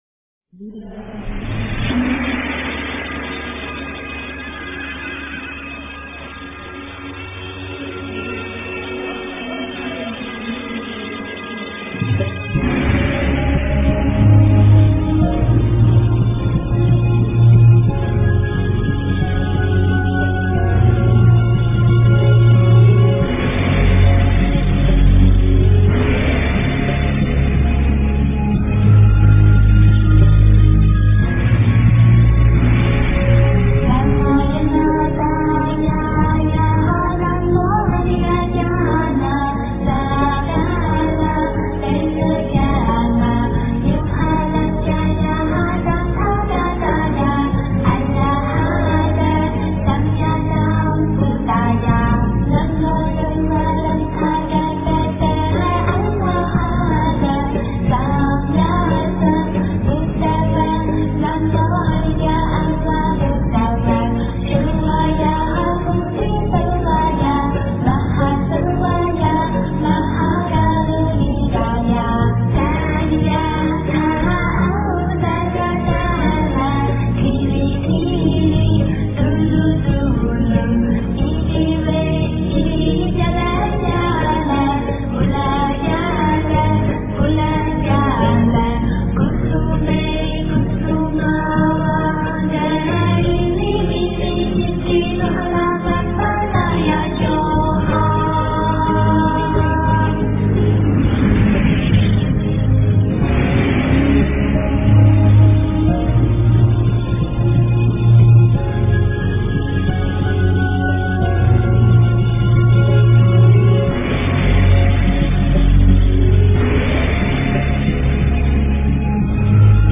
诵经
佛音 诵经 佛教音乐 返回列表 上一篇： 地藏菩萨的故事 下一篇： 大悲咒 相关文章 大势至菩萨心咒--未知 大势至菩萨心咒--未知...